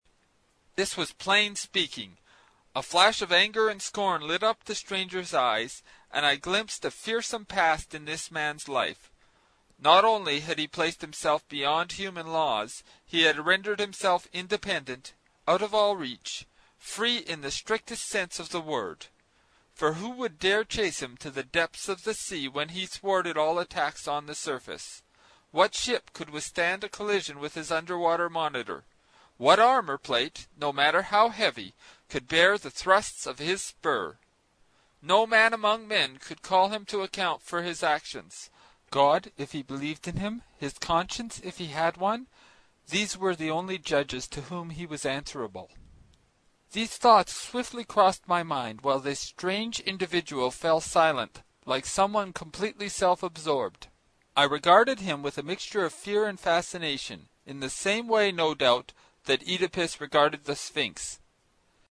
在线英语听力室英语听书《海底两万里》第136期 第10章 水中人(7)的听力文件下载,《海底两万里》中英双语有声读物附MP3下载